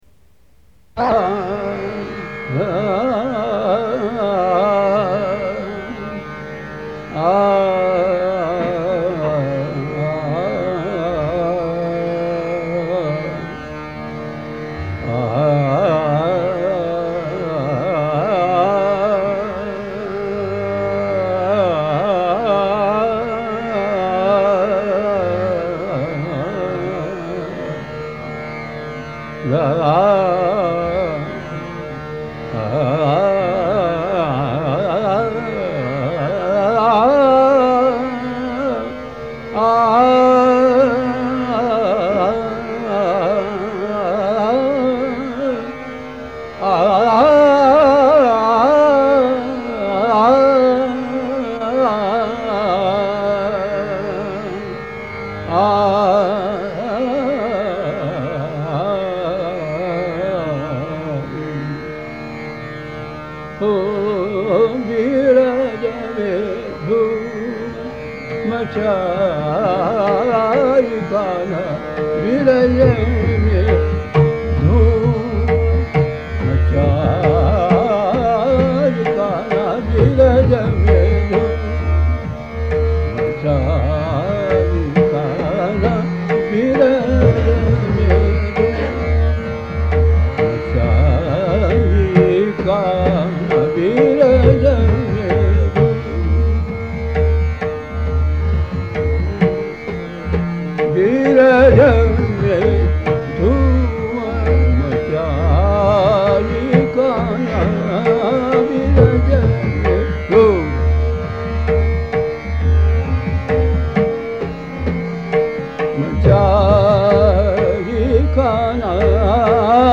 sings Bhimpalas